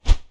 Close Combat Swing Sound 16.wav